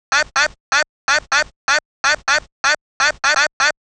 cch_vocal_loop_ep_125.wav